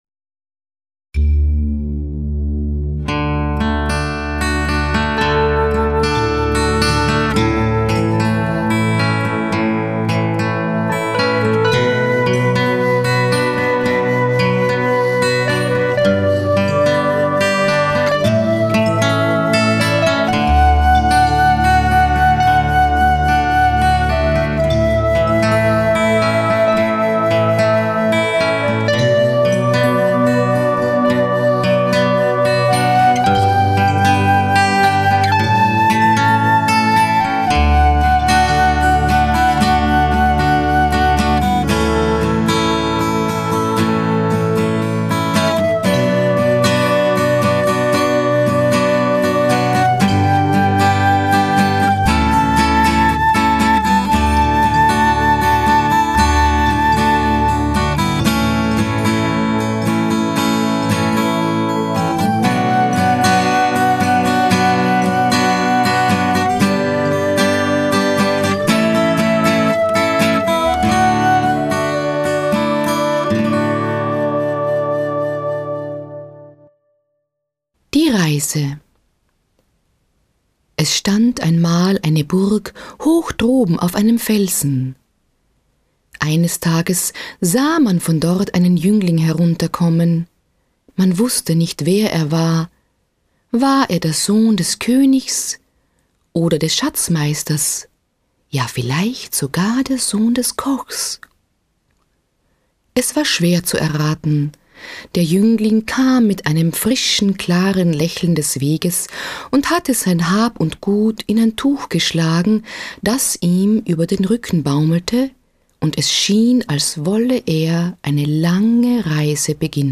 Arrangiert und aufgenommen wurden sie in den SAI-Studios.